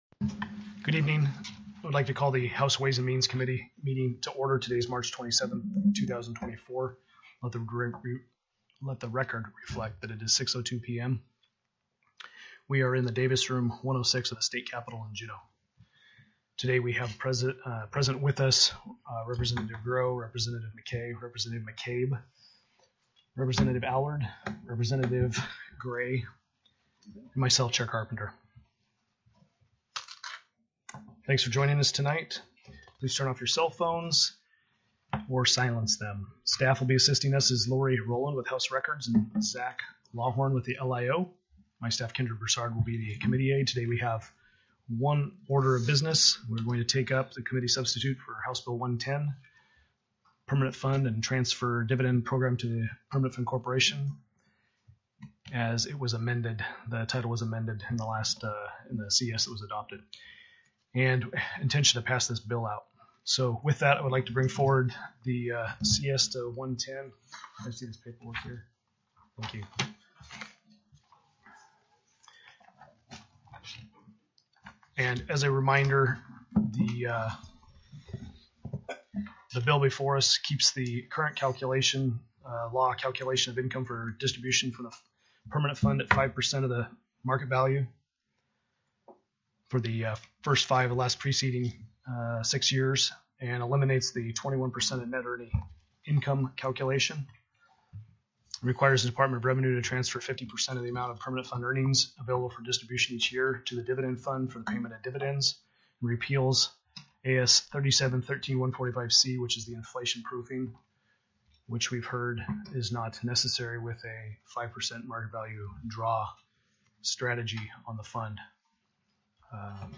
The audio recordings are captured by our records offices as the official record of the meeting and will have more accurate timestamps.
+ teleconferenced
CHAIR  CARPENTER gave  a  synopsis of  the  provisions under  the